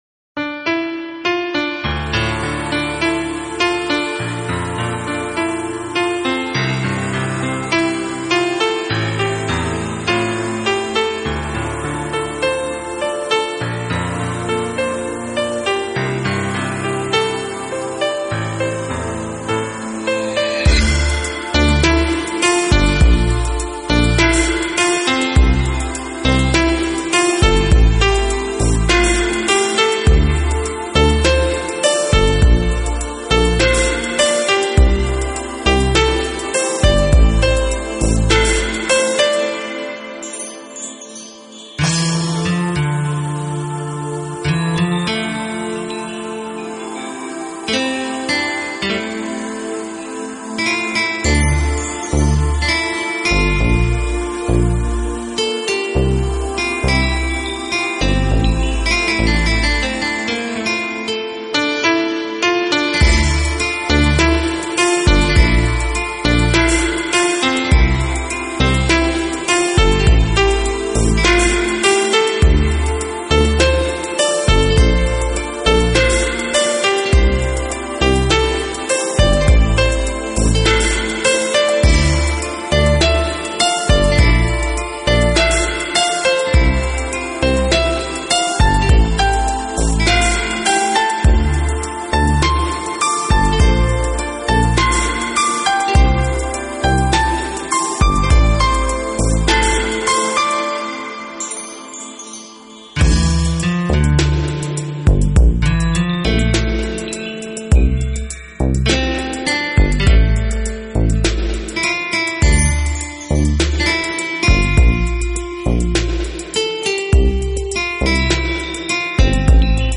士、Fusion、Ambient，Deep House、Lounge 、Chill-Out 等等音乐的聚集，